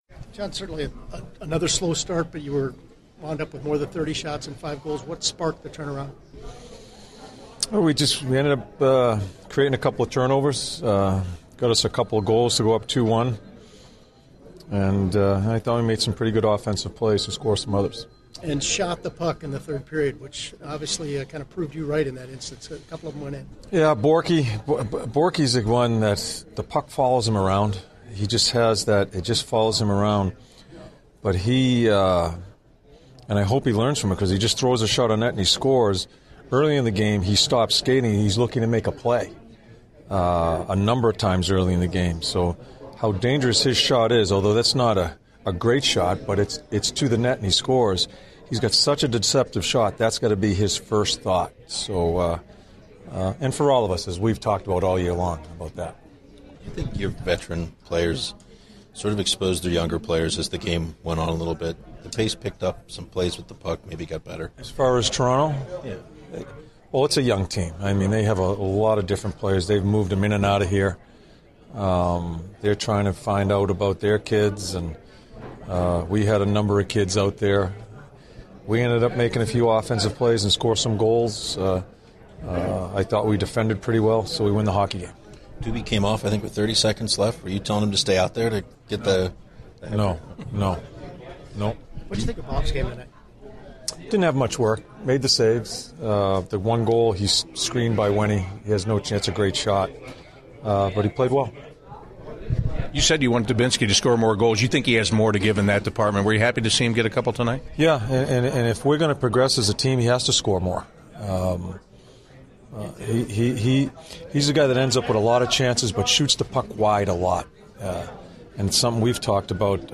John Tortorella Post-Game 04/06/16